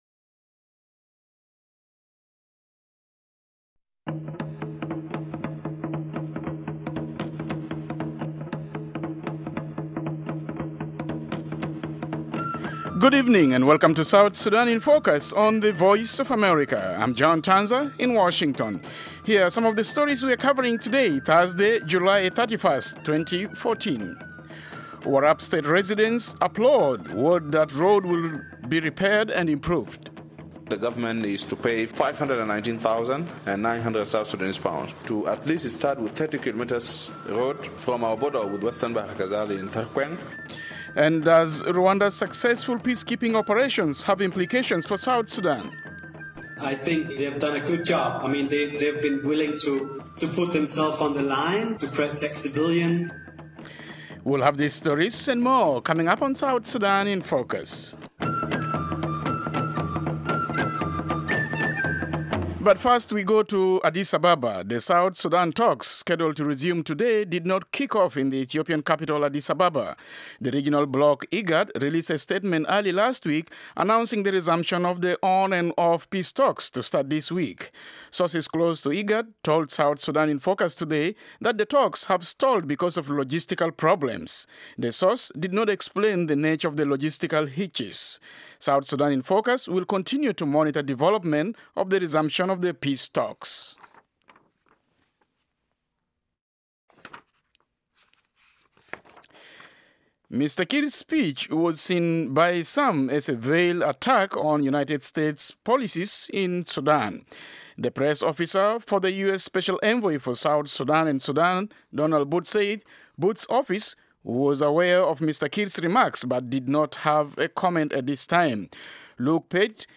South Sudan in Focus is a 30-minute weekday English-language broadcast/internet program covering rapidly changing developments in the new nation of South Sudan and the region.